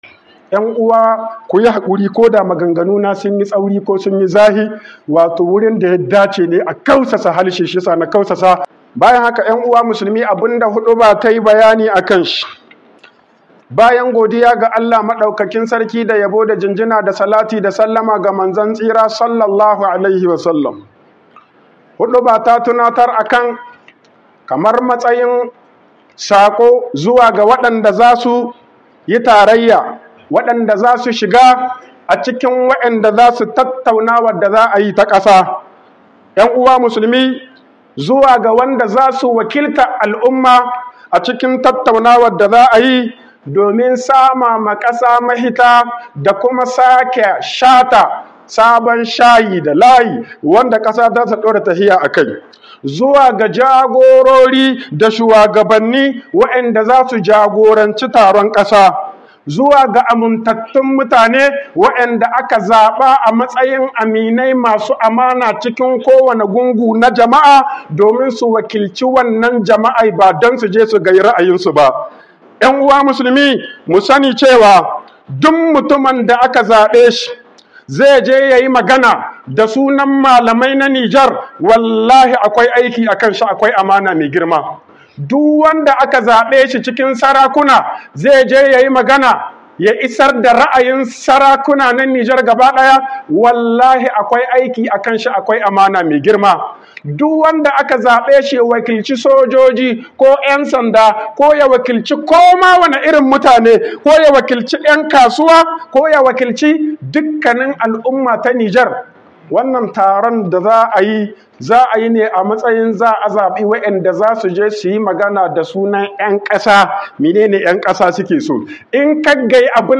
Nasiha ga Mashawartan Gomnati - HUƊUBOBIN JUMA'A